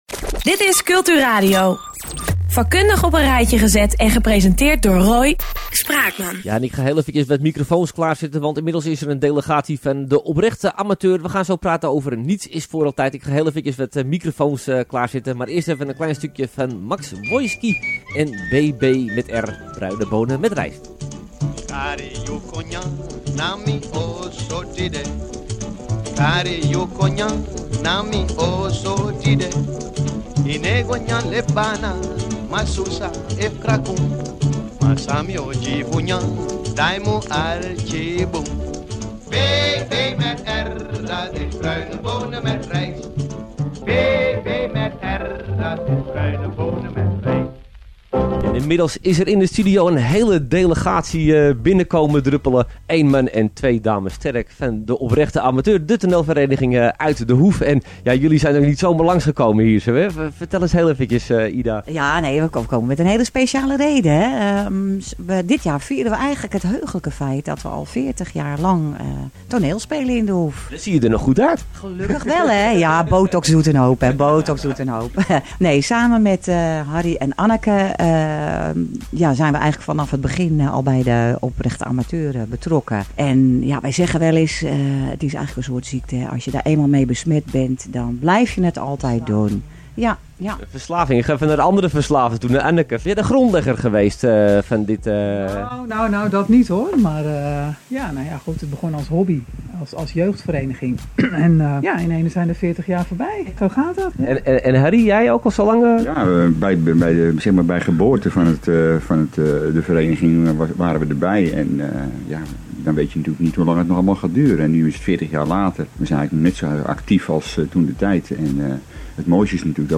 Interview Culturadio